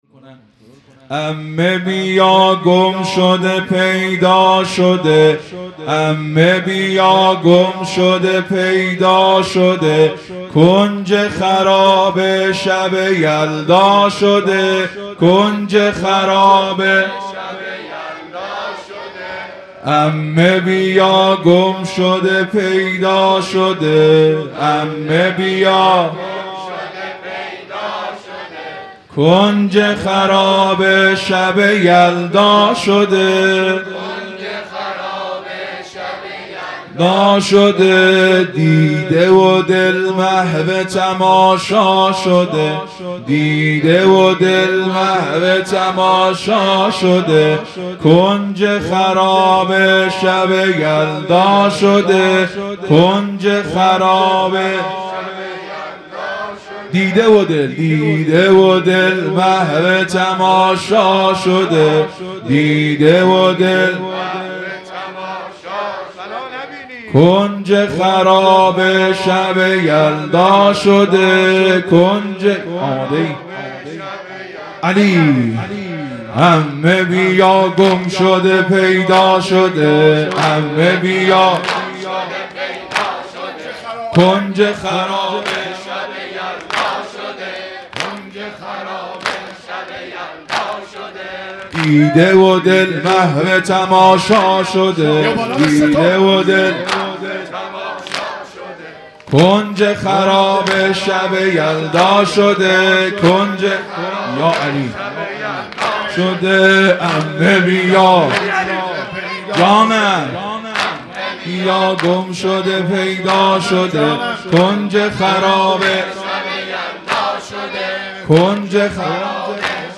دودمه